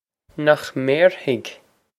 Nokh mare-hig?
This is an approximate phonetic pronunciation of the phrase.